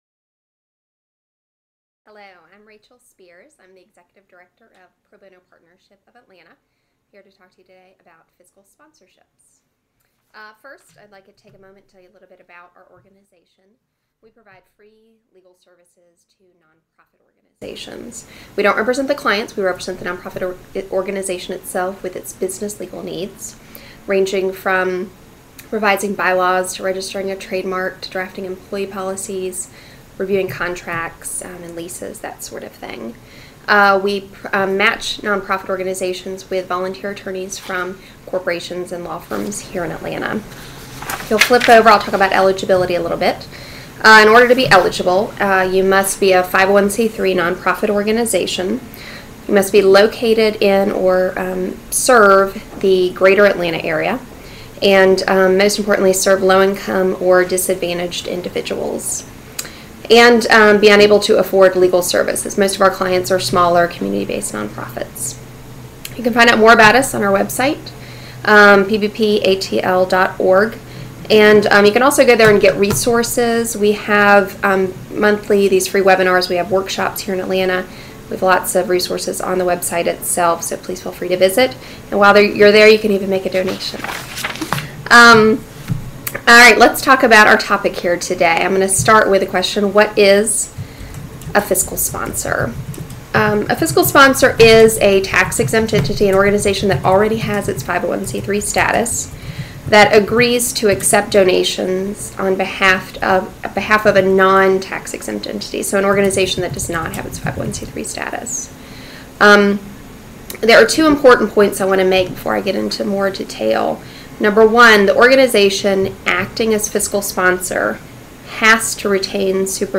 During this one hour webinar, our speaker discusses this arrangement under which a charitable project without 501(c)(3) status might benefit from the tax-exempt status and administrative support of a sponsoring organization, including:
However, the audio content remains clear and valuable , especially for those interested in the subject matter.